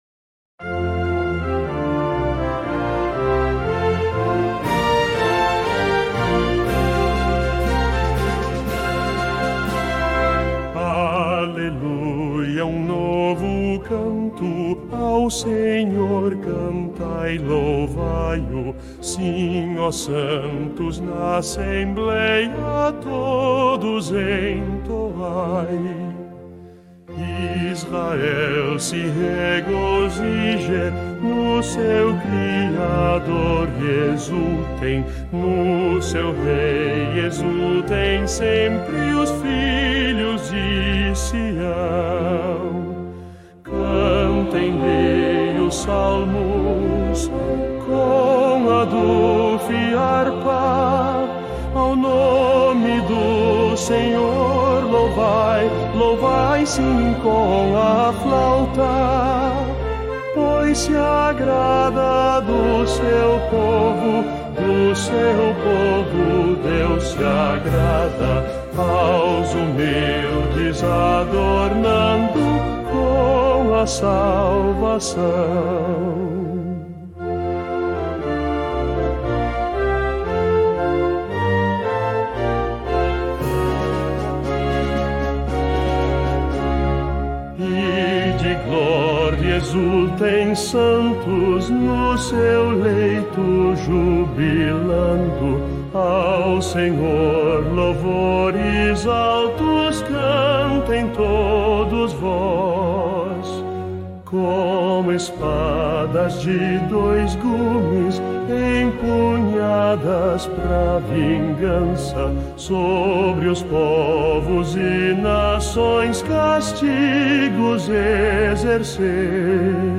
Melodia tradicional galesa
salmo_149B_cantado.mp3